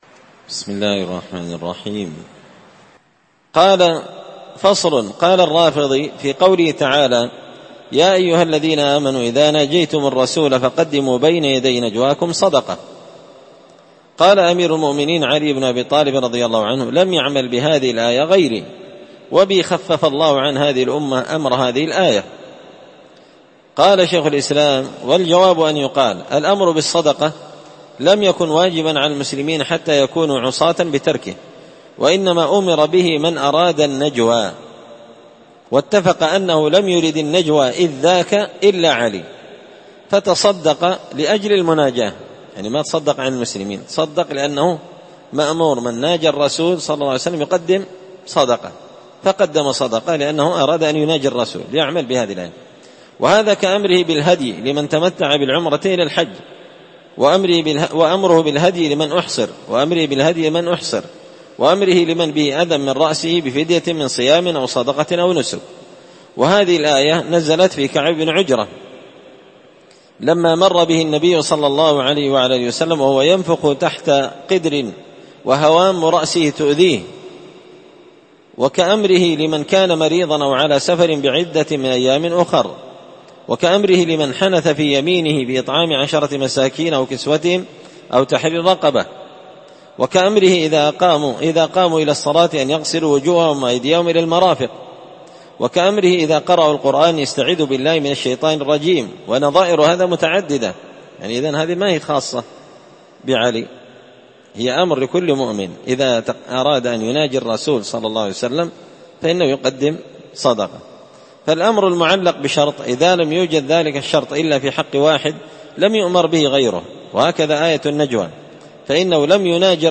الأربعاء 11 ذو القعدة 1444 هــــ | الدروس، دروس الردود، مختصر منهاج السنة النبوية لشيخ الإسلام ابن تيمية | شارك بتعليقك | 15 المشاهدات